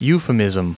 Prononciation du mot euphemism en anglais (fichier audio)
Prononciation du mot : euphemism